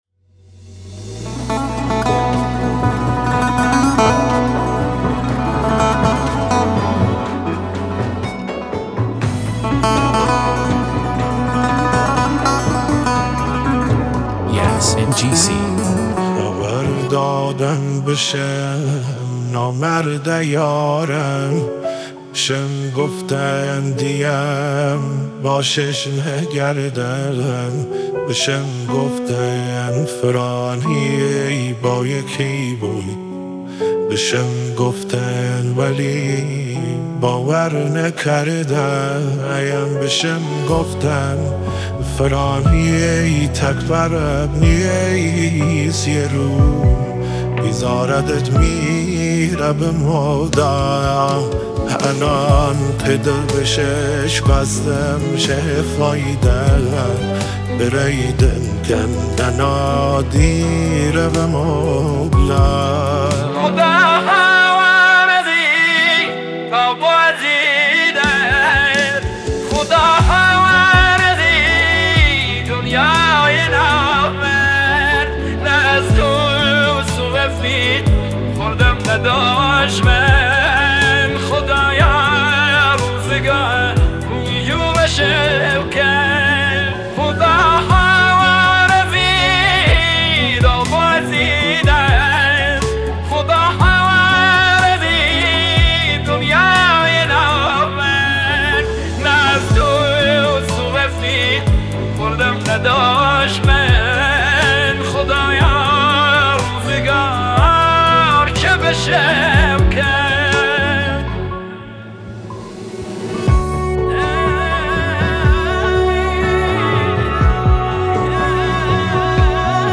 غمگین
آهنگ کردی